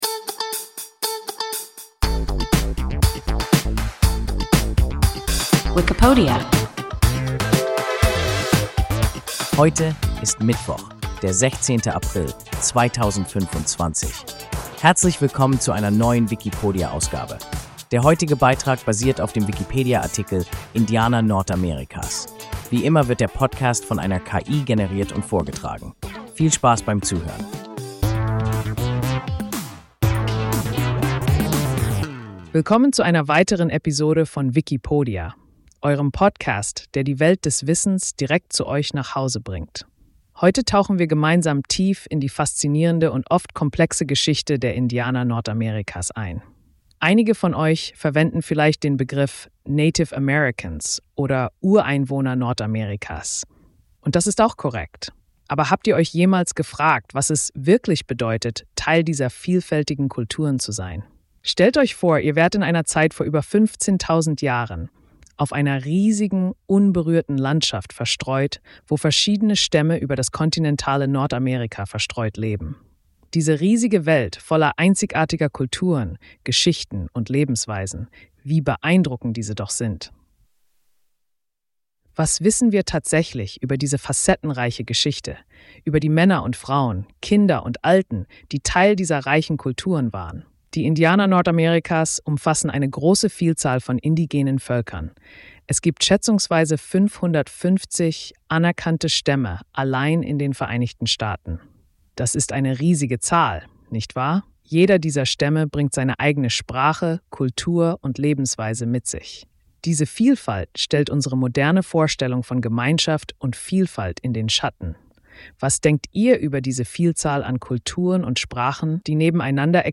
Indianer Nordamerikas – WIKIPODIA – ein KI Podcast